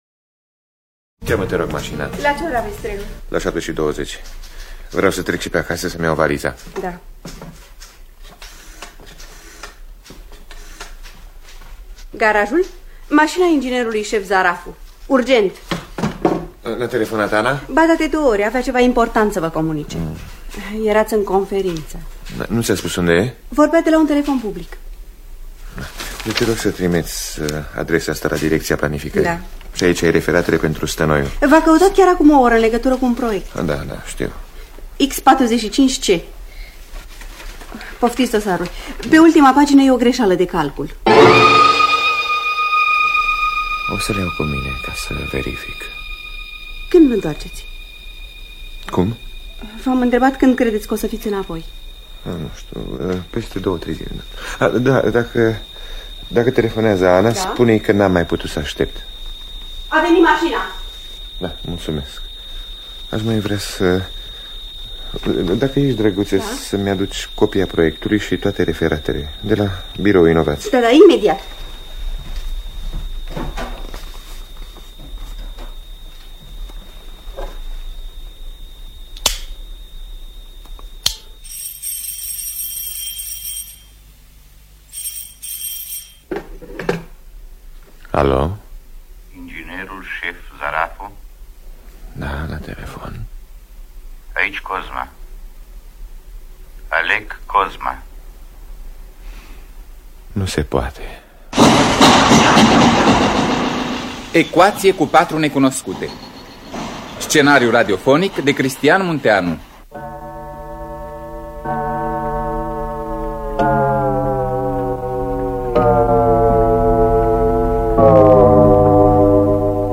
Teatru Radiofonic Online